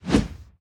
swing_big_a.ogg